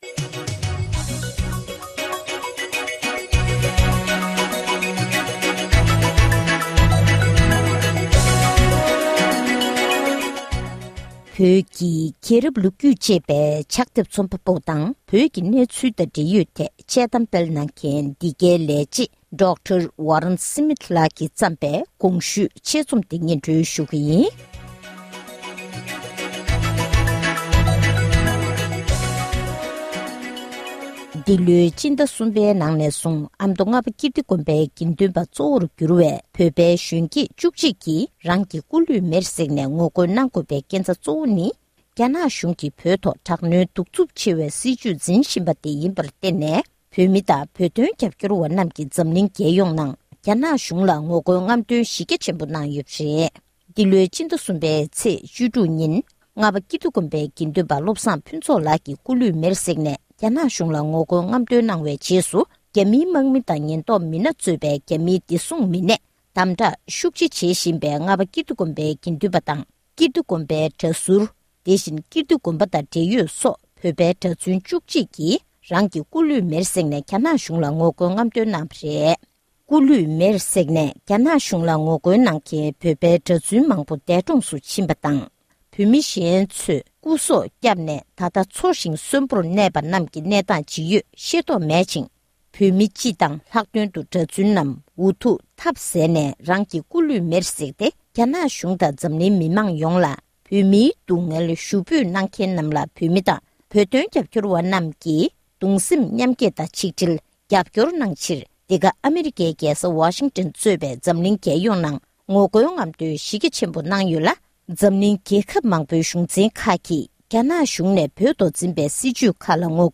ཕབ་བསྒྱུར་དང་སྙན་སྒྲོན་གནང་བར